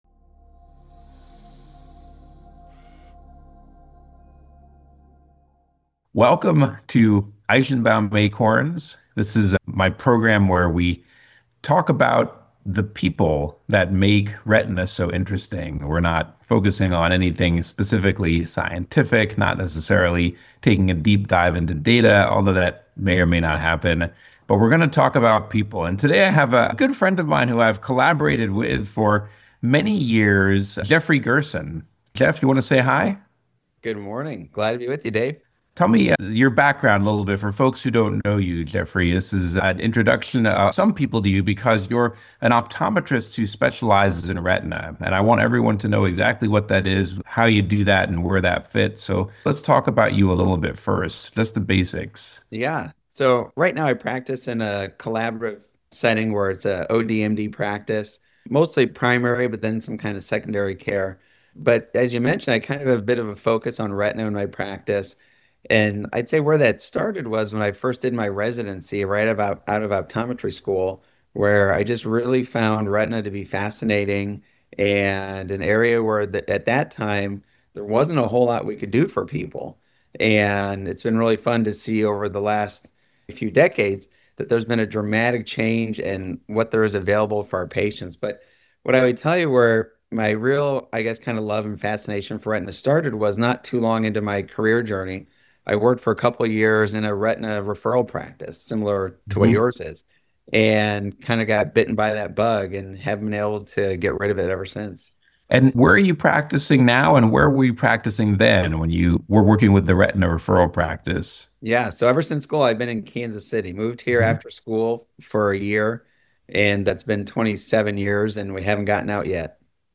This podcast series explores the overlooked corners of the retina profession through candid conversations with thought leaders.